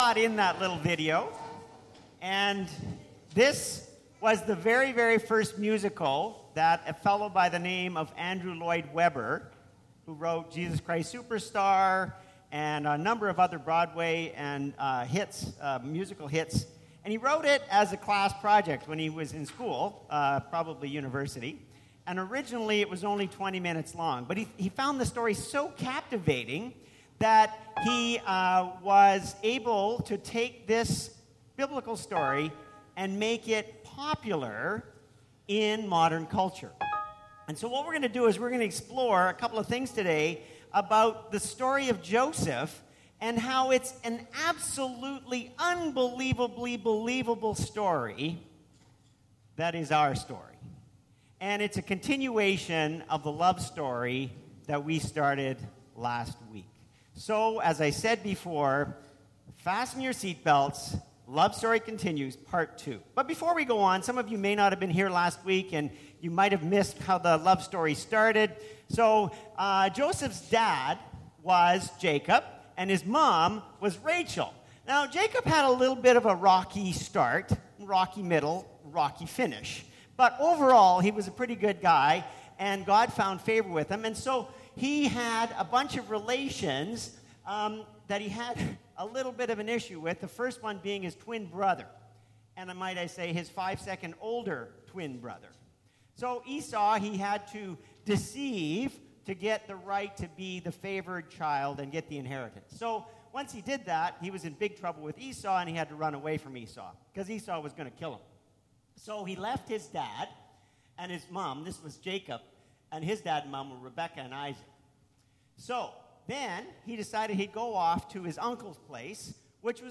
PG Sermons